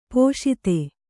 ♪ pōṣite